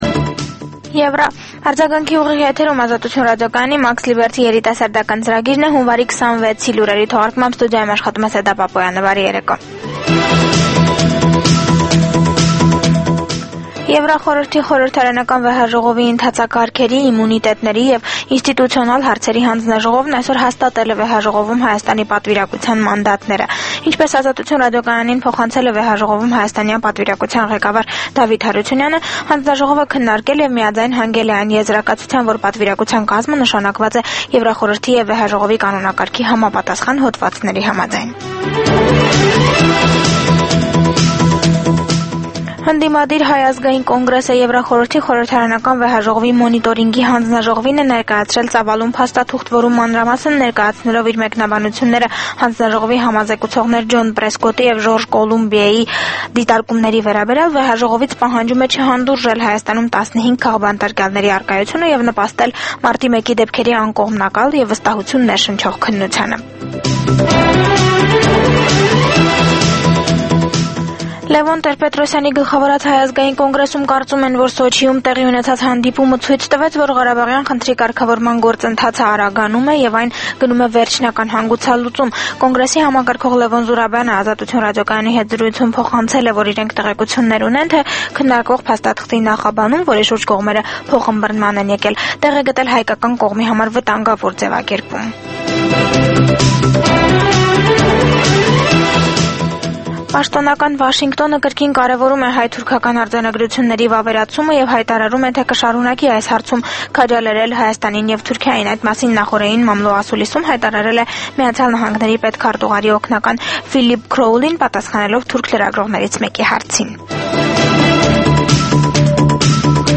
Լուրեր
Տեղական եւ միջազգային վերջին լուրերը ուղիղ եթերում: